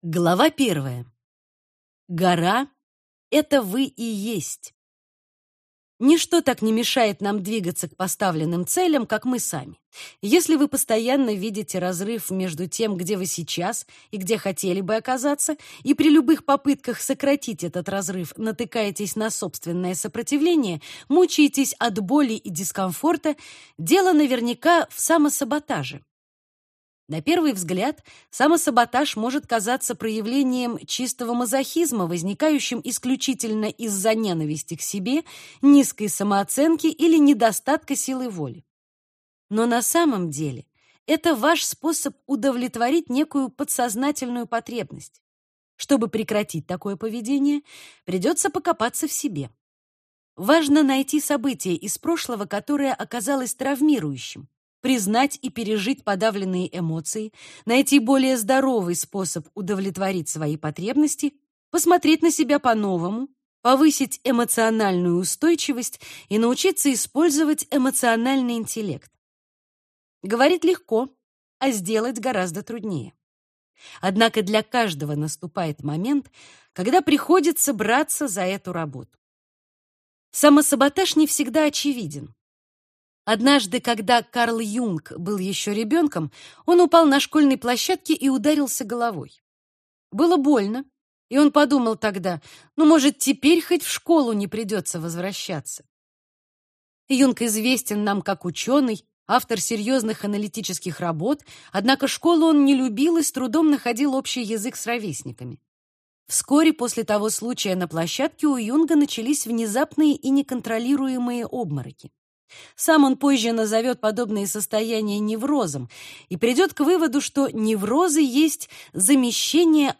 Аудиокнига От самосаботажа к саморазвитию. Как победить негативные внутренние установки на пути к счастью | Библиотека аудиокниг